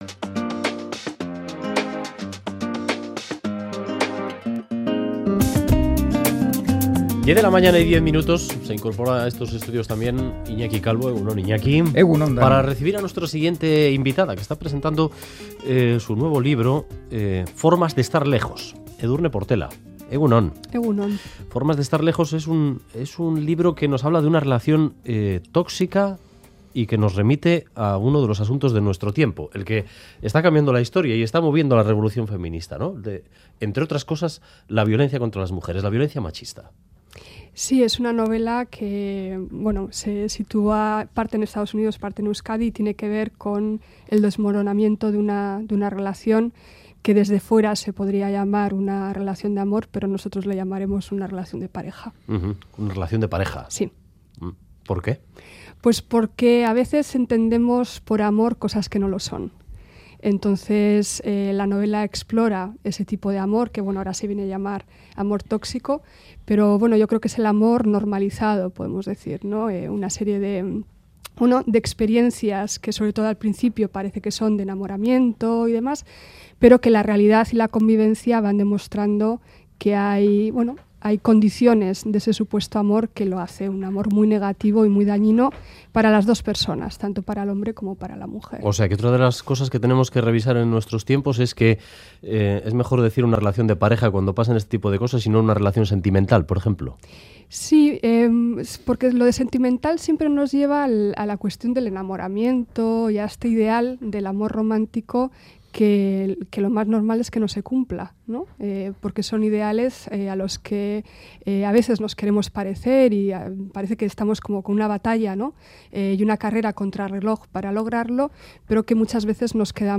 Audio: La escritora Edurne Portela visita 'Boulevard' para presentar su novela 'Formas de estar lejos', donde aborda un tema candente: el de la violencia de género.